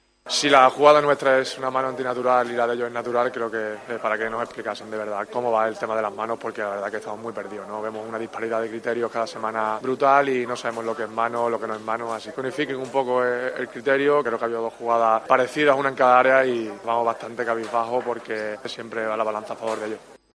AUDIO: El técnico del Elche habló en rueda de prensa del polémico penalti pitado a favor del Barcelona y de las dudas que hay sobre el tema de las manos.